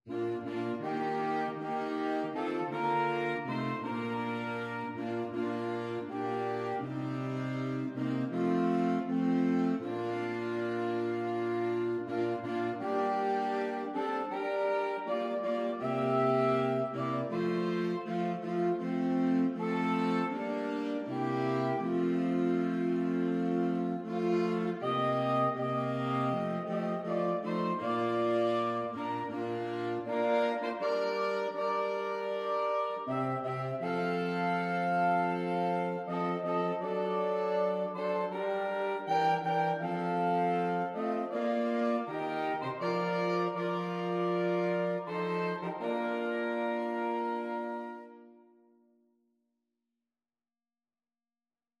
Saxophone Quartet version
Andante
4/4 (View more 4/4 Music)
Saxophone Quartet  (View more Easy Saxophone Quartet Music)
Christmas (View more Christmas Saxophone Quartet Music)